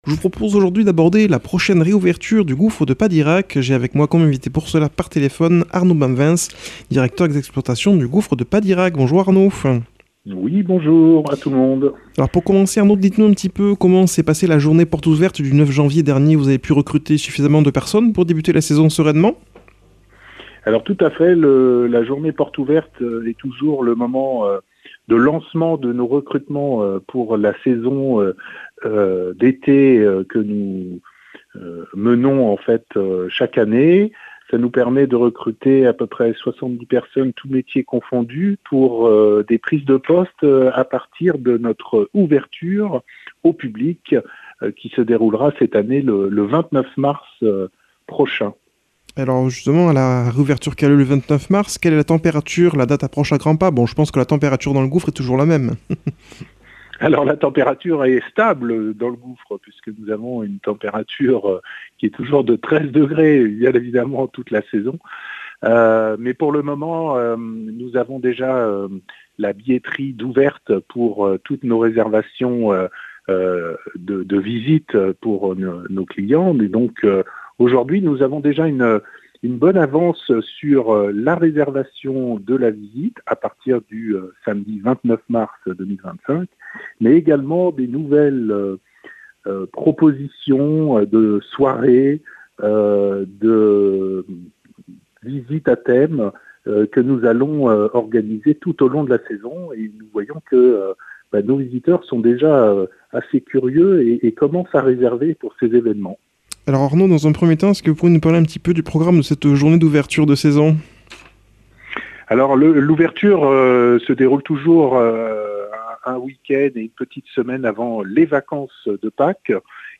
invité par téléphone